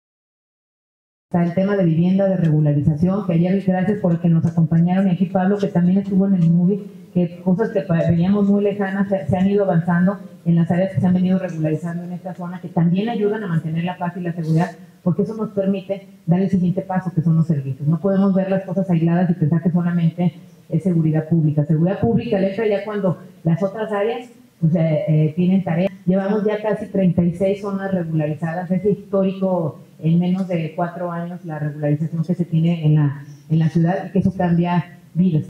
Audio de Alejandra Gutiérrez, alcaldesa de León: